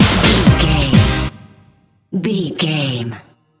Aeolian/Minor
Fast
drum machine
synthesiser
electric piano
bass guitar
conga